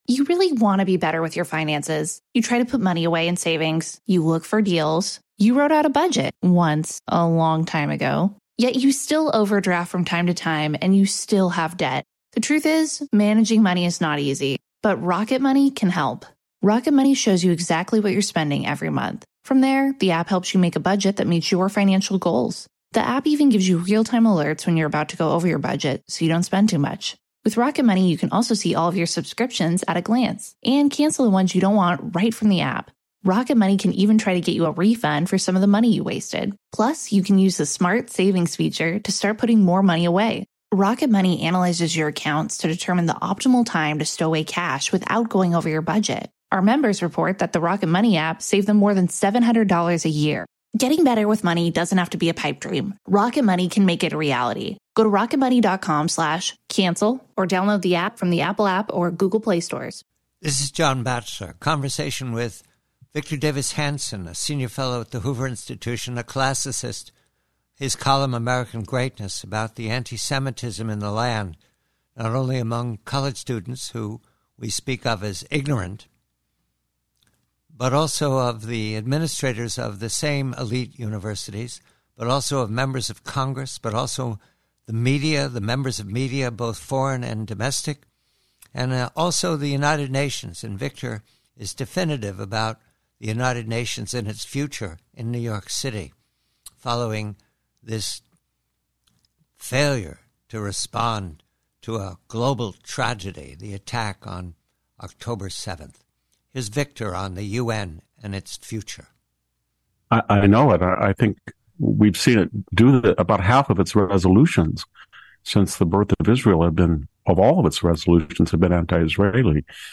PREVIEW: From a much longer conversation with Victor Davis Hanson of Hoover re antisemitism in America, the professor turns specialy to the future of the UN following unacceptable conduct and remarks by the UN leadership about the predation and sexual abuse and mass-murder on ...